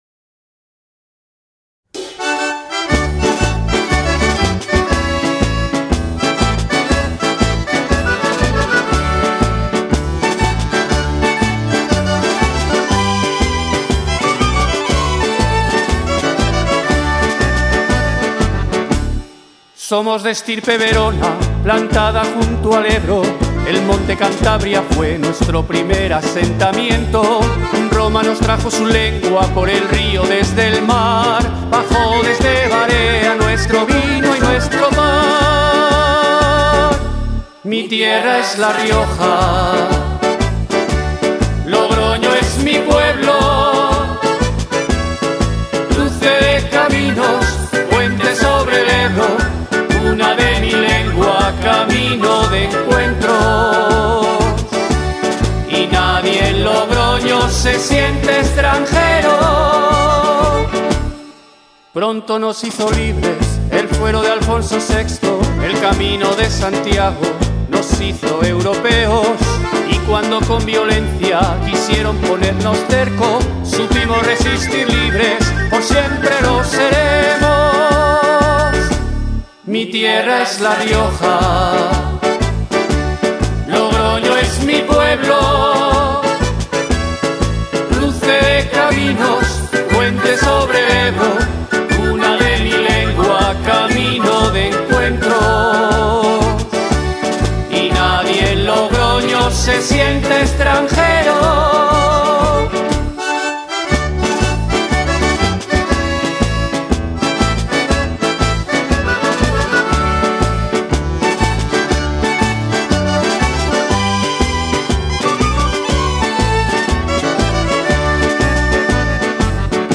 Una jota que rememora hitos de la historia de la ciudad.